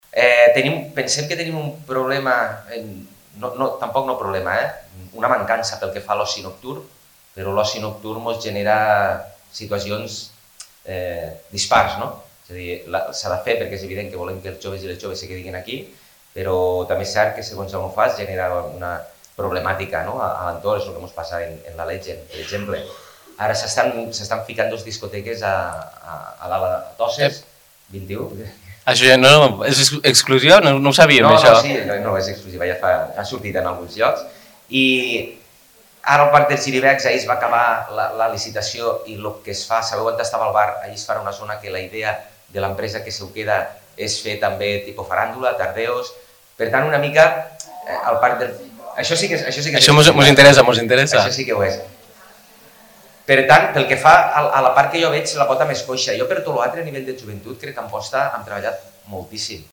L’alcalde d’Amposta, Adam Tomàs, ha explicat a 21 Ràdio que una empresa interessada en la licitació del nou bar del Parc dels Xiribecs voldria organitzar festes d’oci juvenils com els populars ‘tardeos’.